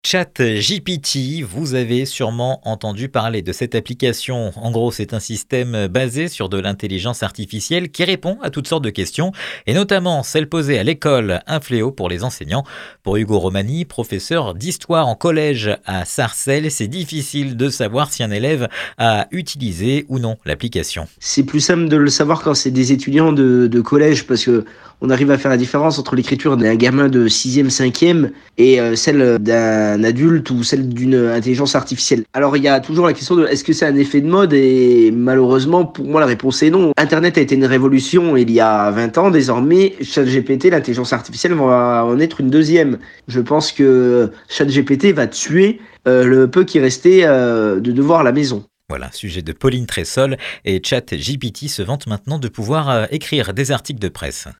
professeur d'histoire en collège.